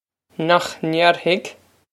Pronunciation for how to say
Nokh nayr-hig?
This is an approximate phonetic pronunciation of the phrase.